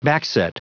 Prononciation du mot backset en anglais (fichier audio)
Prononciation du mot : backset